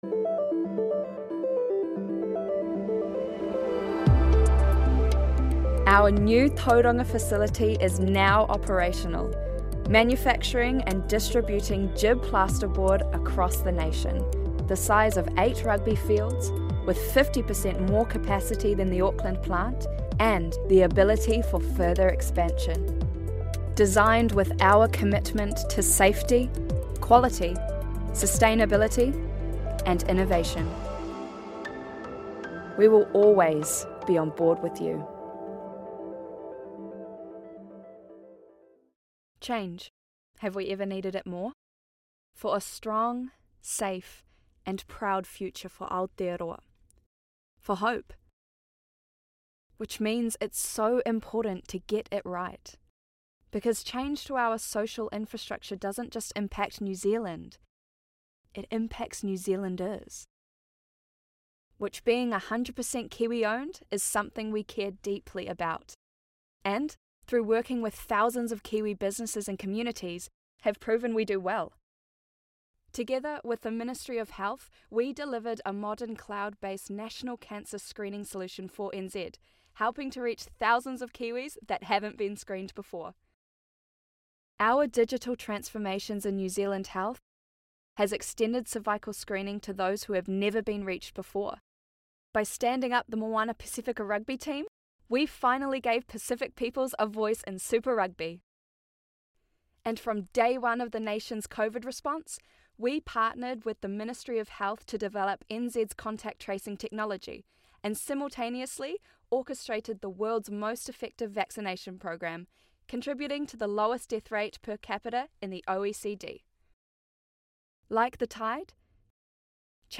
Demo
Young Adult, Adult
australian | character
british rp | character
new zealand | natural
conversational
warm/friendly